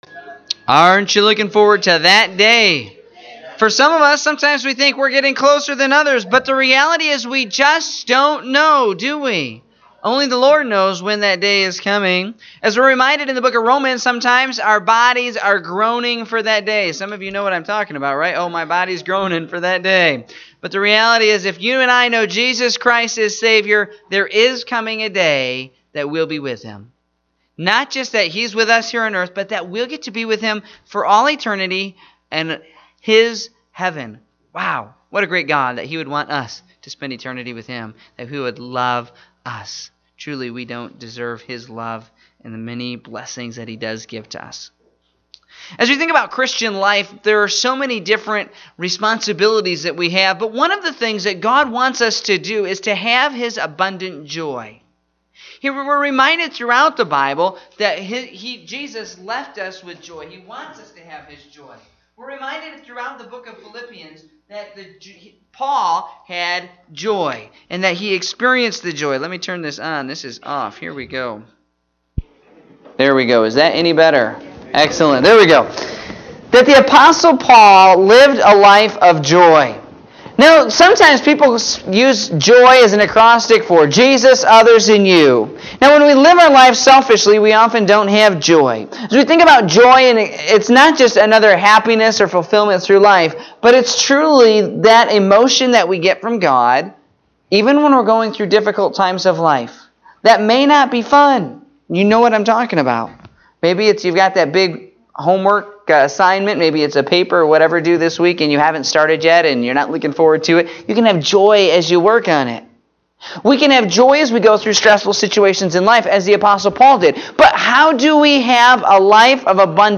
Morning Service (3/11/2018)